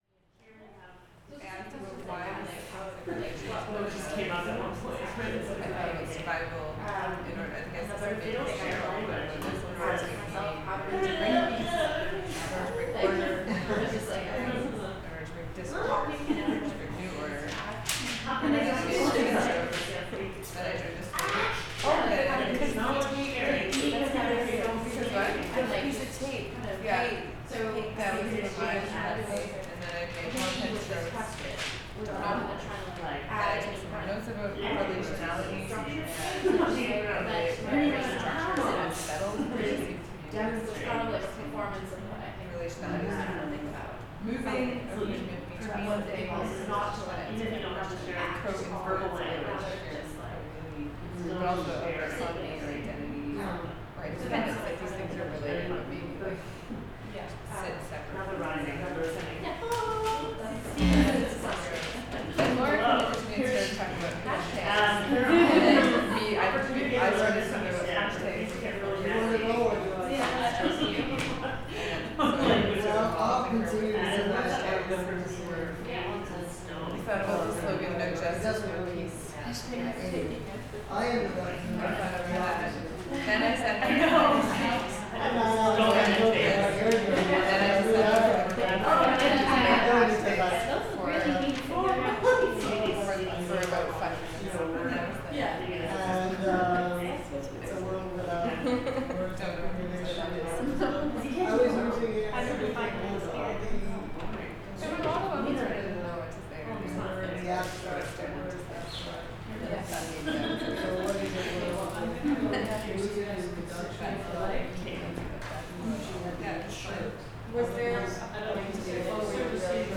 This participatory, score-based workshop was taken up by members of the Knot Working Group as part of a series of seminars and gatherings looking at relationships between voice, fiction, collectivity and publication.
The workshop was presented as one of several events which took place in the Courtroom at the Arts Court, in Ottawa – a site or legal proceeding which provoked reflection on the weight of language and utterance as world-producing acts.